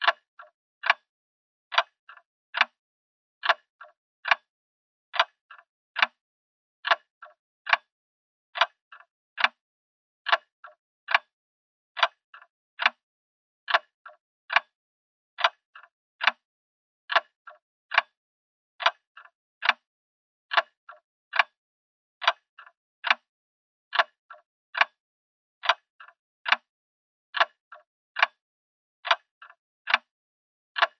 Categoría Alarmas